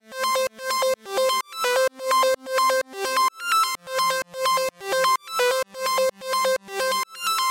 Tag: 128 bpm Dance Loops Synth Loops 1.26 MB wav Key : A